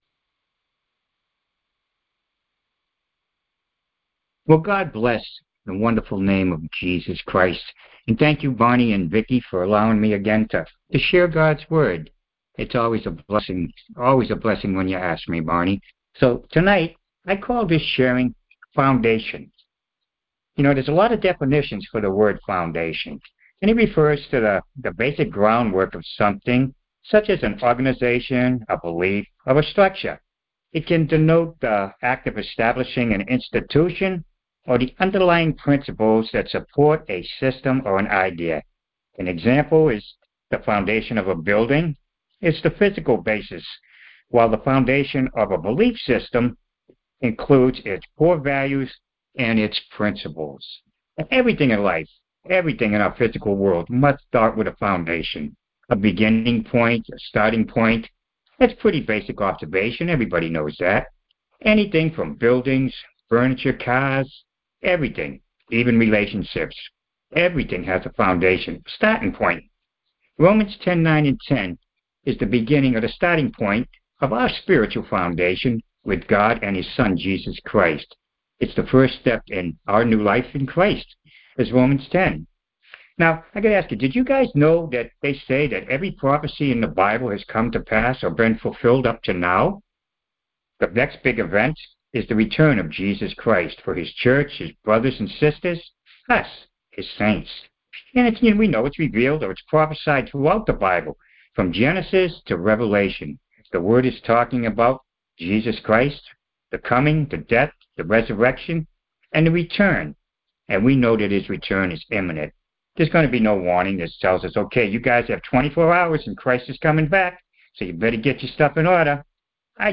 Details Series: Conference Call Fellowship Date: Thursday, 05 February 2026 Hits: 83 Scripture: Romans 10:9-10 Play the sermon Download Audio ( 7.30 MB )